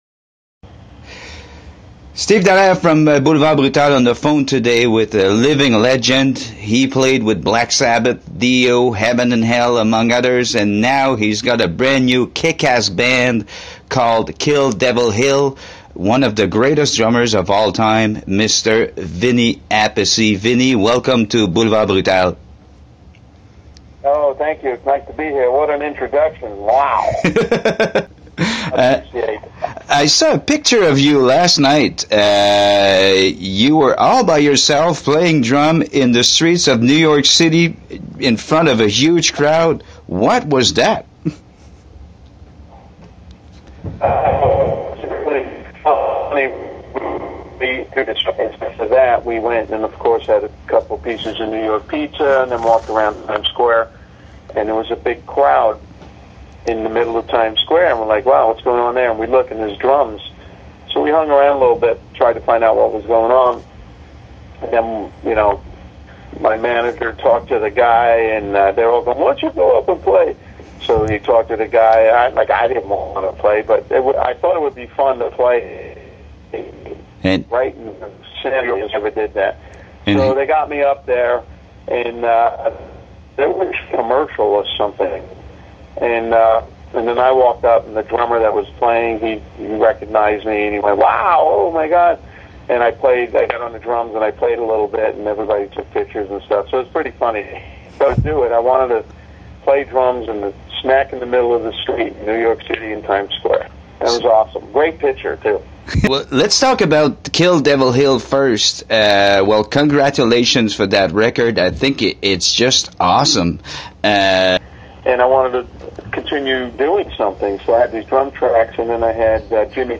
J’ai eu la chance la semaine dernière de m’entretenir avec une légende du heavy métal, le batteur Vinny Appice.
Voici quelques extraits de cet entretien, vous pouvez entendre l’entrevue au complet dans un lien plus bas. Le pauvre avait quelques problèmes avec son cellulaire, il a d’ailleurs dû en utiliser un autre en cours d’entrevue, il a donc fallu que je fasse beaucoup de montage en enlevant certains passages inaudibles, mais le tout s’est rectifié en milieu d’entrevue.
vinny-interview.mp3